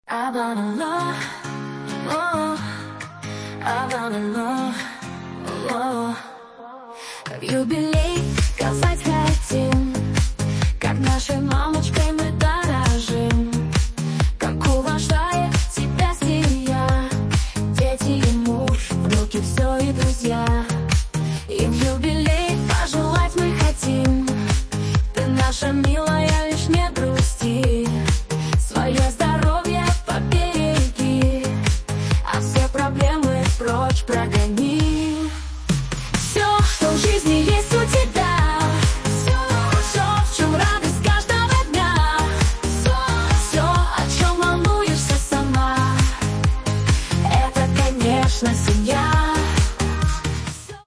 Песня переделка на юбилей маме
Фрагмент варианта исполнения: